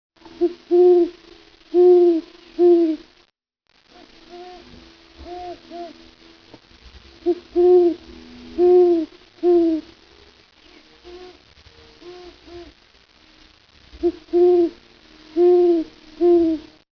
great horned owl
Two Great Horned Owls (Bubo virginianus) talk to each other on a mid-march night at Peck's Lake [Arizona]. They're sitting about 150 meters apart, and a third was calling about a half mile away. These owls nest in the steep rocky cliffs around the lake and hunt in the flatlands surrounding the lake and marsh. I have "compressed" this file, the actual time between calls is about 15 seconds, but the time between the query and response is accurate.